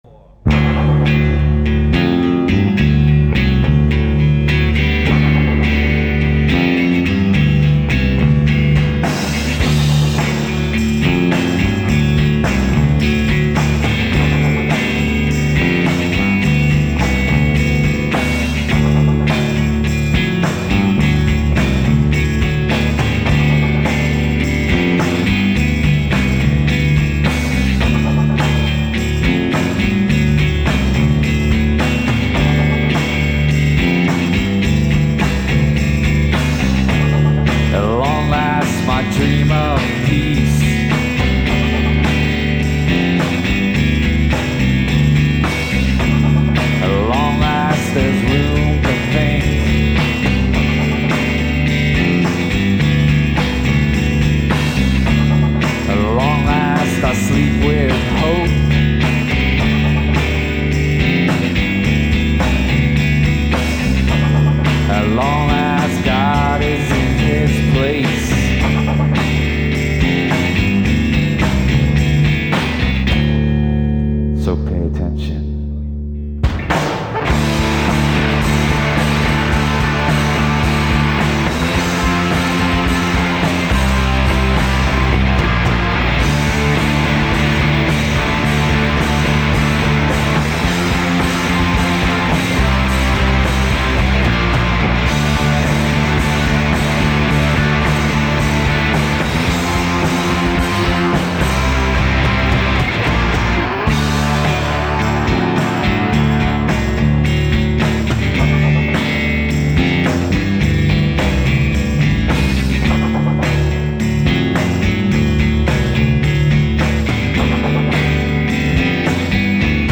enregistrée le 10/04/1997 au Studio 104